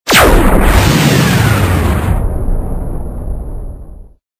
hugelaser.ogg